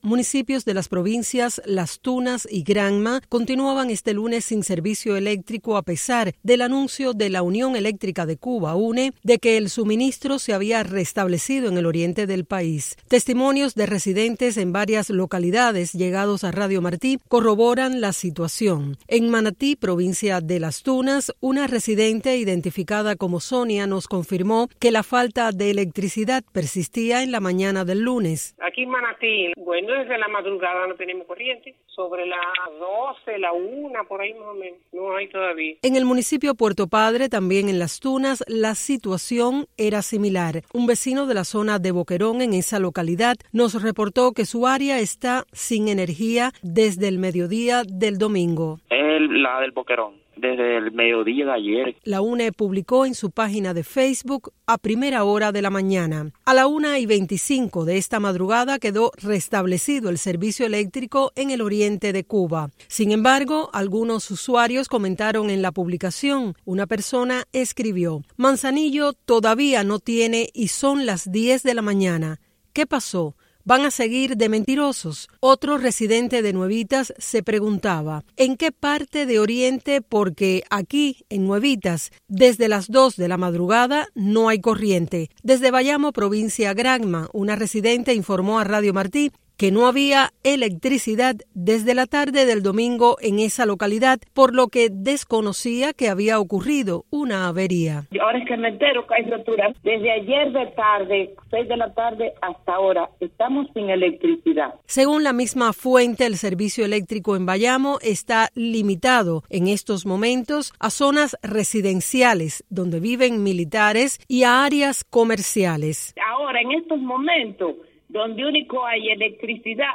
Testimonios de residentes locales recogidos por Radio Martí evidencian la persistencia de cortes de electricidad, alimentando la frustración y escepticismo entre los afectados.